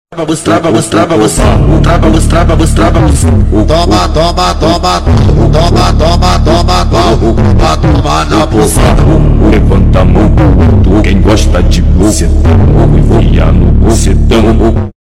Girl revving the 15mio $ sound effects free download
Girl revving the 15mio $ Bugatti La Voiture Noire